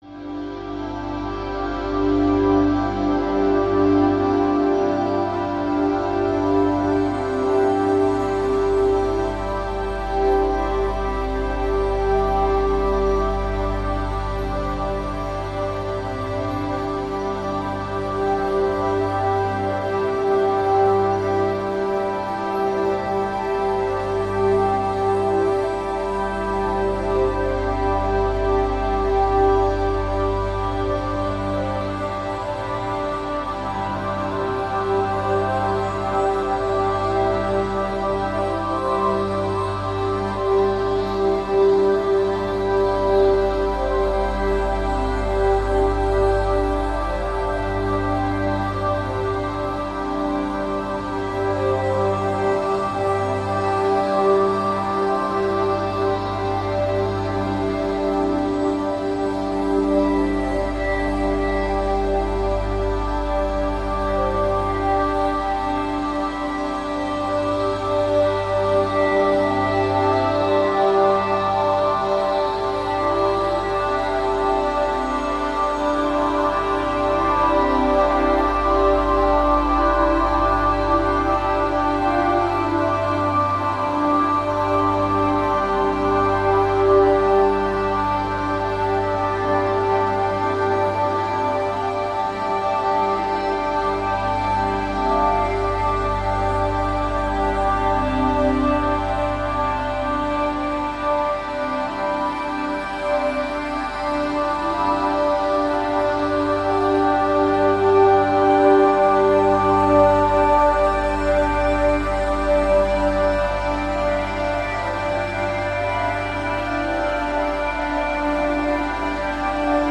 Long Tonal Uplifting Textures Tonal, Uplifting Textures